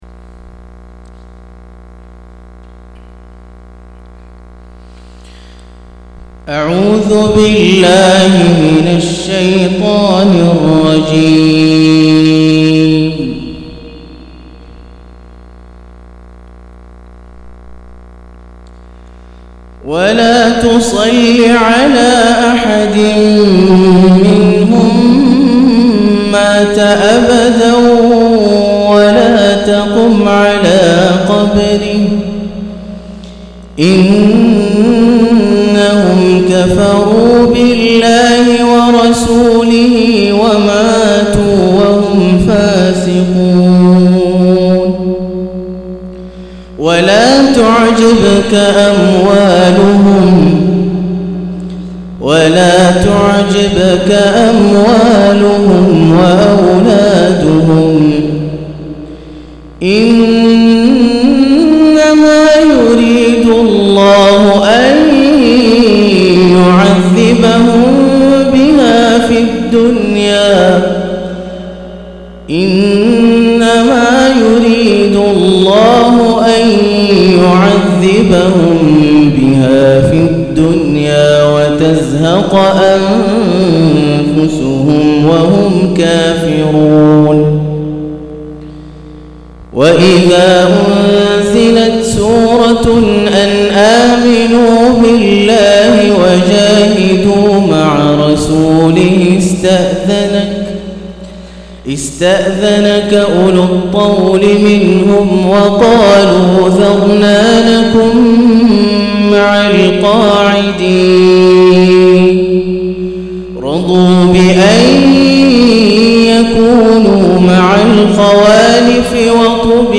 190- عمدة التفسير عن الحافظ ابن كثير رحمه الله للعلامة أحمد شاكر رحمه الله – قراءة وتعليق –